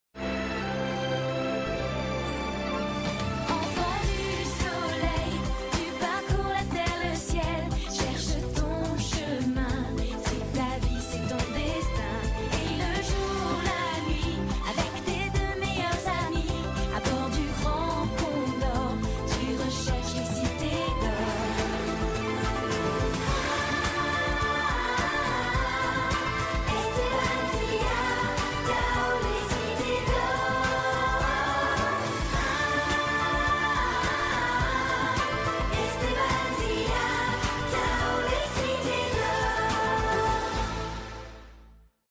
Générique